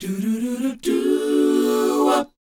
DOWOP E 4A.wav